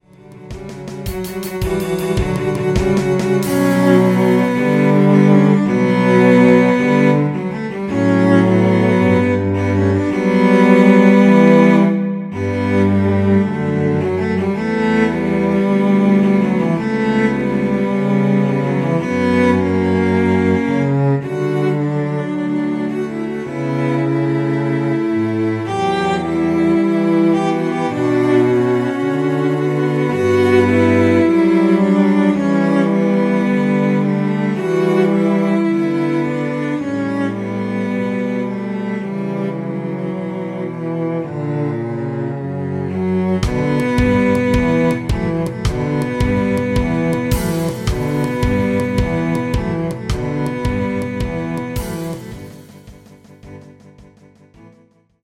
Besetzung: Cello-Trio mit Schlagzeug
Tempo / Satzbezeichnung: Very rhythmic and accentuated
Tonart: h-Moll